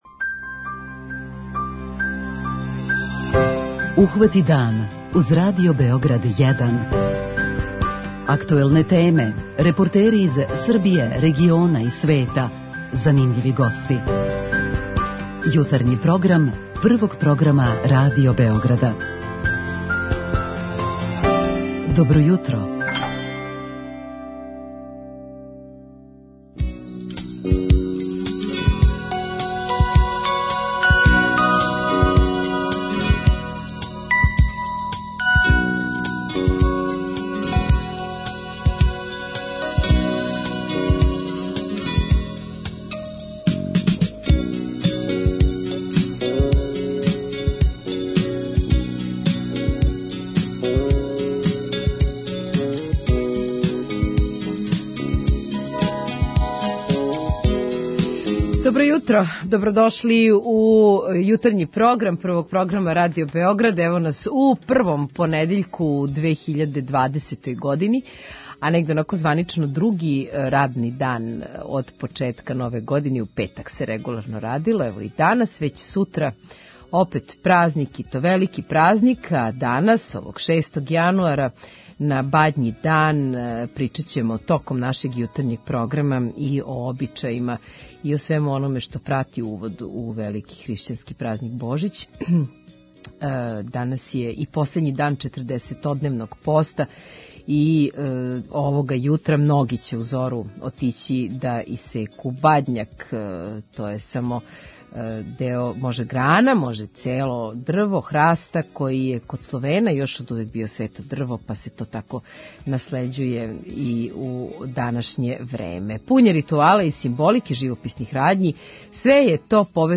Укључићемо у програм и репортере широм земље од којих ћемо сазнати како теку припреме за Божић.